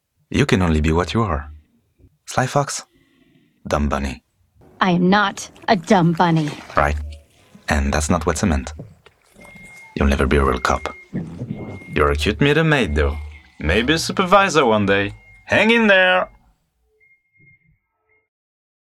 15 - 60 ans - Baryton